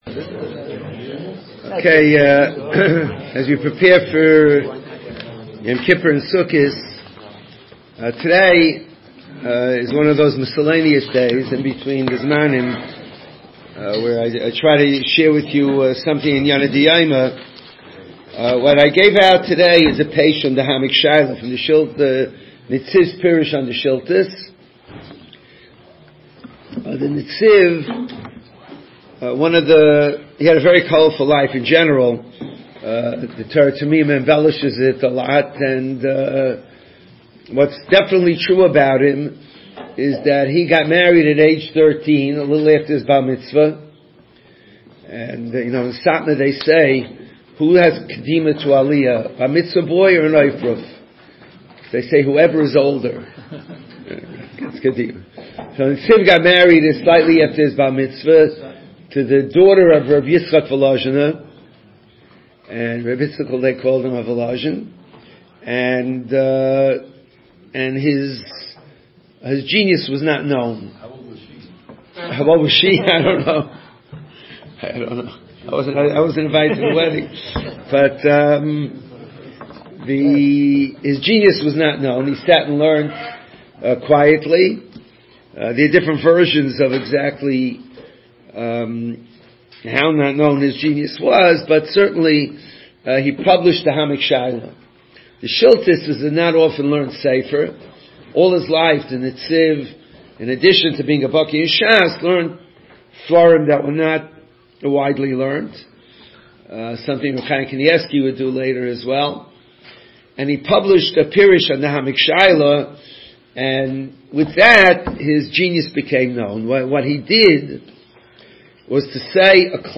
Shiurim and speeches on Gemarah, Halachah, Hashkofo and other topics, in mp3 format
Shiur for Succos Haemik Shaila 5786.MP3